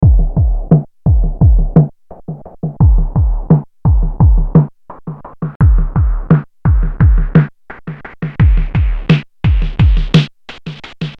86-BPM-downbeat-drum-loop.mp3